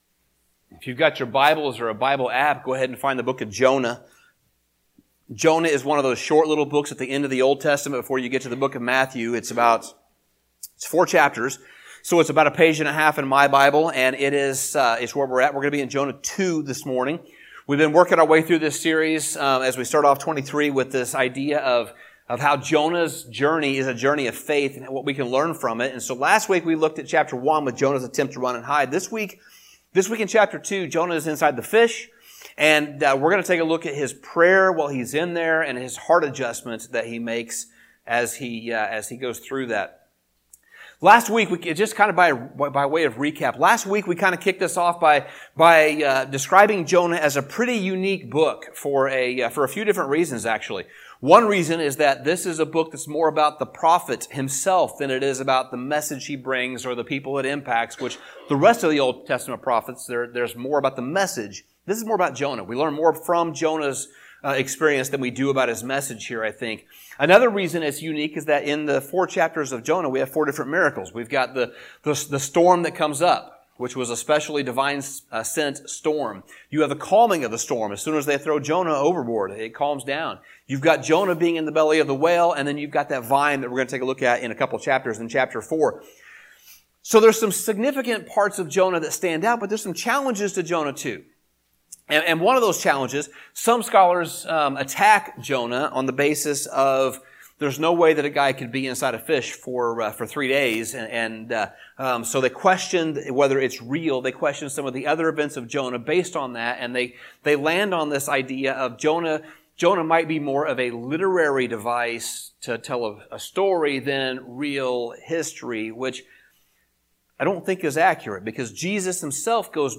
Sermon Summary In the second chapter of Jonah, the setting is inside the fish that swallows this man.